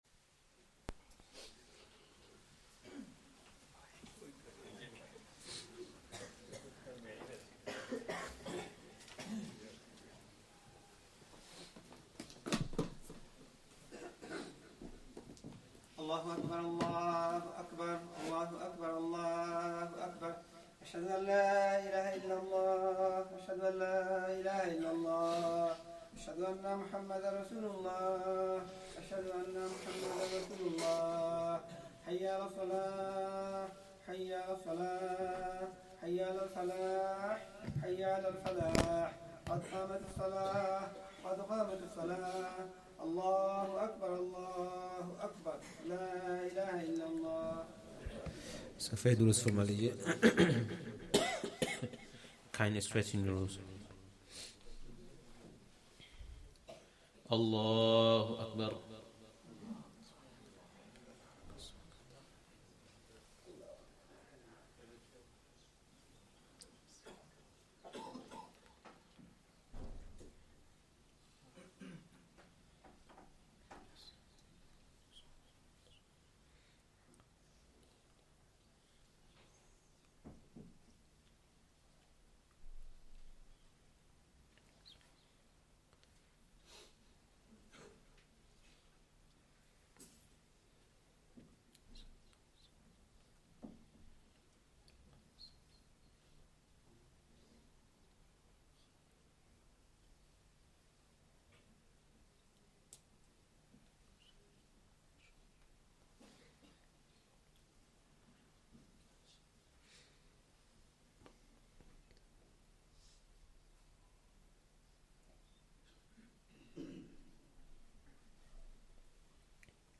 Asr salah + Bayaan
Masjid Adam, Ilford